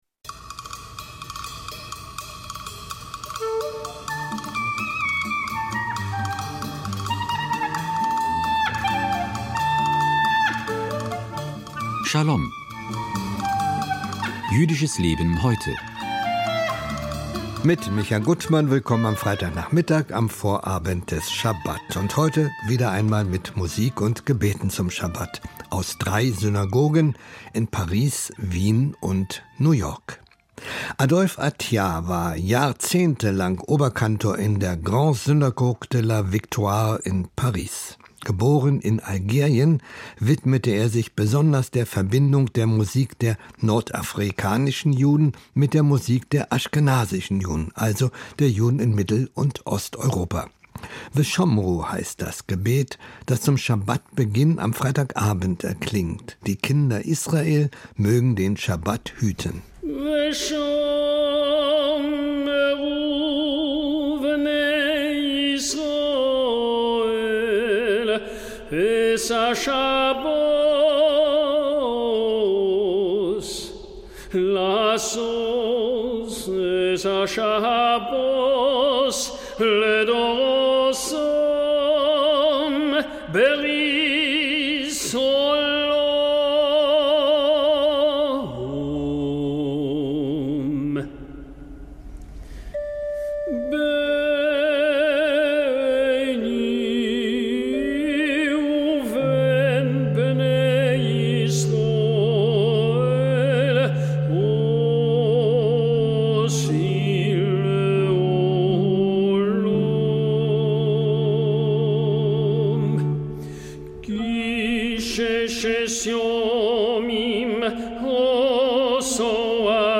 Musik und Gebete zum Schabat